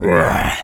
lizard_hurt_moan_01.wav